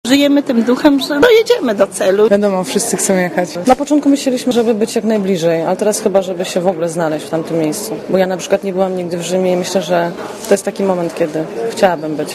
Pielgrzymi
jada_-pielgrzymi.mp3